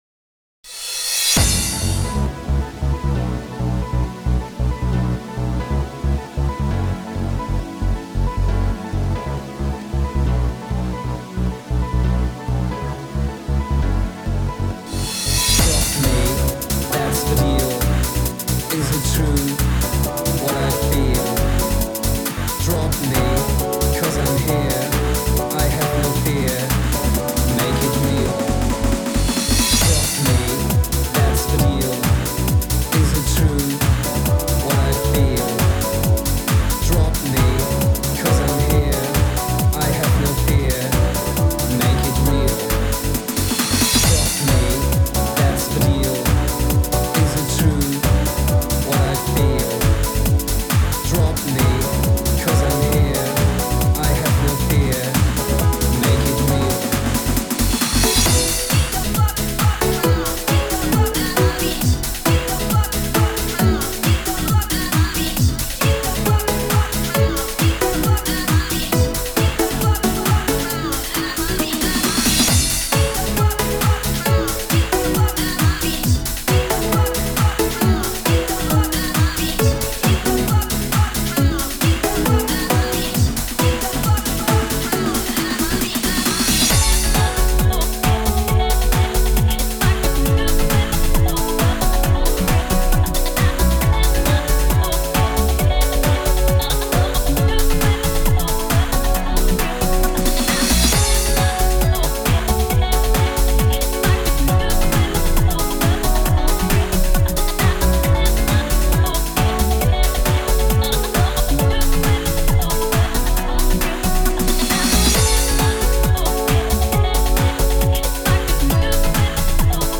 orthodox house
正統派HOUSEという感じで初心に帰り、作り方の視点を変えて仕上げました。